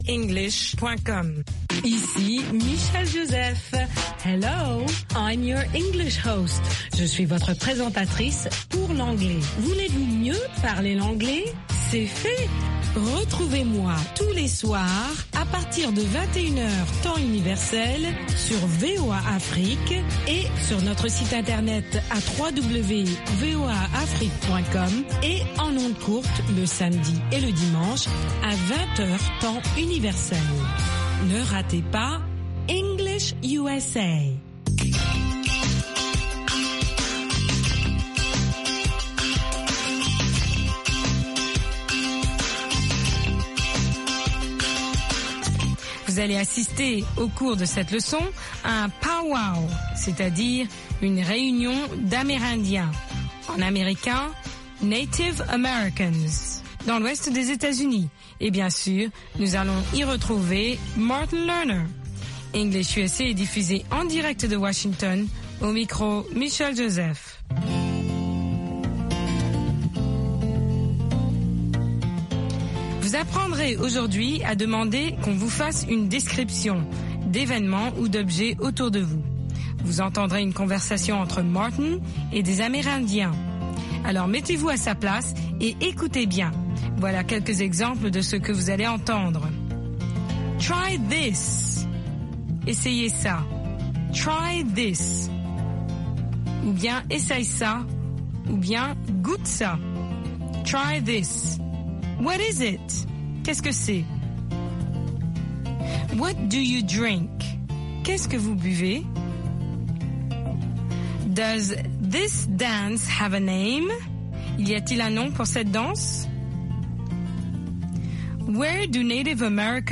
Des conversations en anglais américain par des Africains, qui ont trait à la vie quotidienne au Sénégal. Ces leçons font partie de notre programme hebdomadaire d’apprentissage de l’anglais.Pour nos sujets hebdomadaires, consulter notre page Facebook.